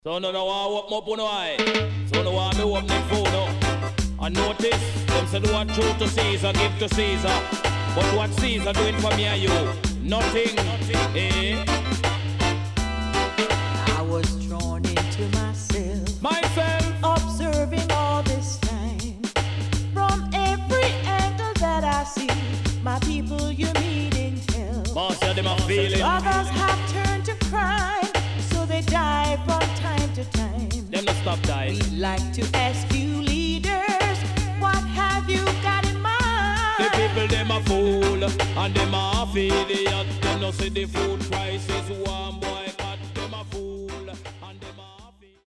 HOME > REISSUE USED [DANCEHALL]